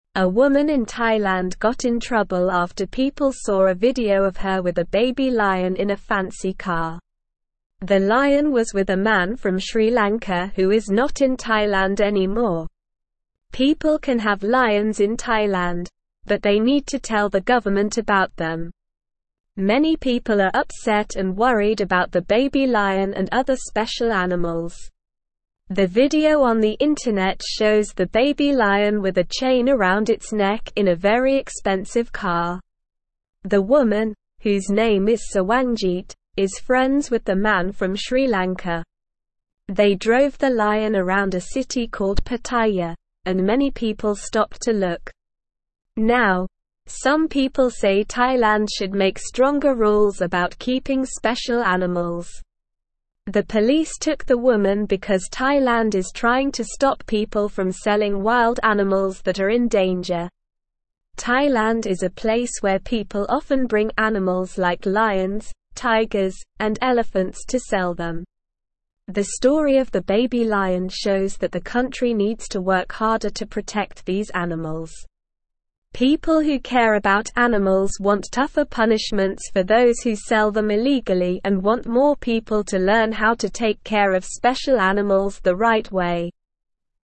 Slow
English-Newsroom-Lower-Intermediate-SLOW-Reading-Trouble-for-Woman-Who-Let-Baby-Lion-Ride-in-Car.mp3